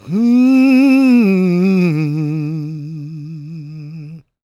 GOSPMALE005.wav